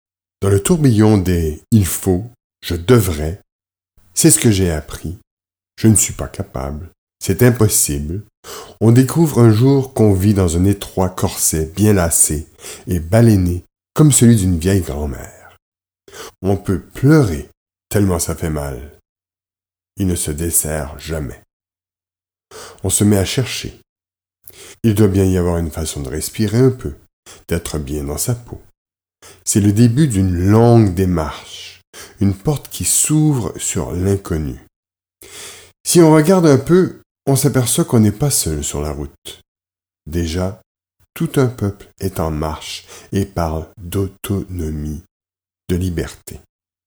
Fondé sur la recherche en psychologie, ce livre-audio constitue un réel programme de changement personnel que les auteurs ont expérimenté et perfectionné.